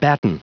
Prononciation du mot batten en anglais (fichier audio)
Prononciation du mot : batten